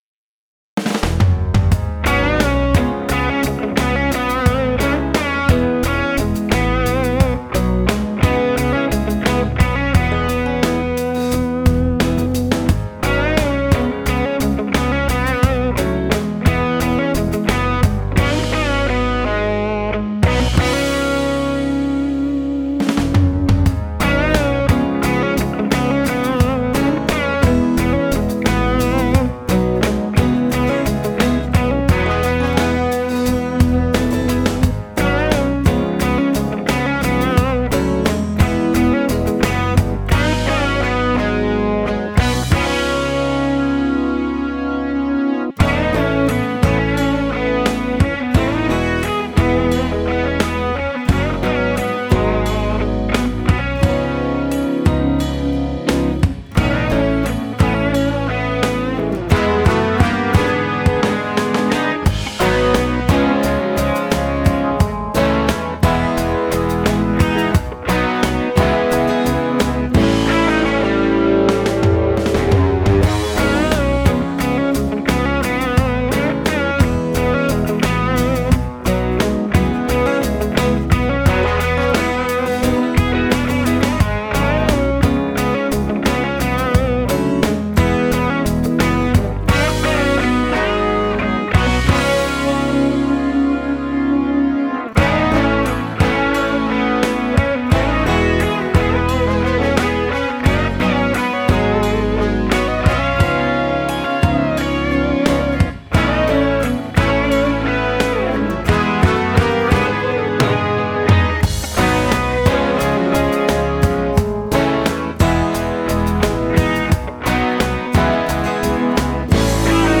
'68 335 lead guitars with some Strat and SG Junior on rhythms, also some Martin acoustic.
All guitars recorded by RecPro Audio VAC Preamp (vintage tube reproduction). Steely / Sneaker inspired.